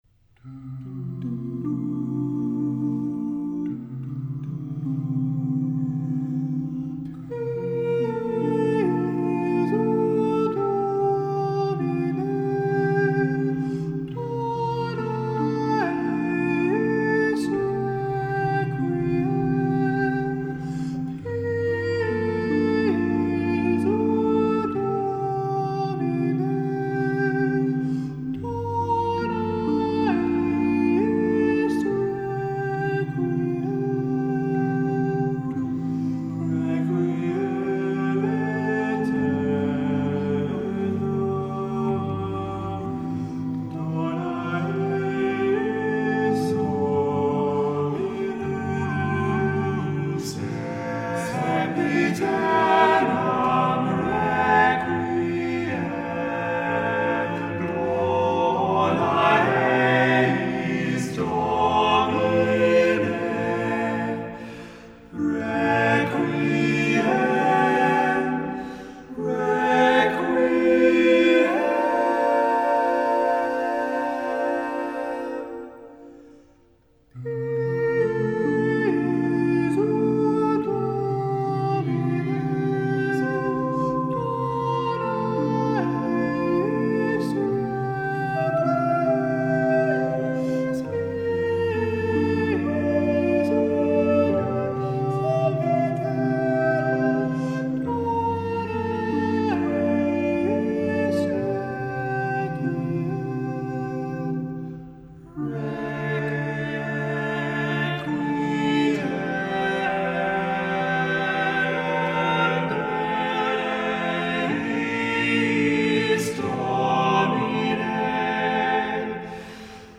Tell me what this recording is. Voicing: SATTBB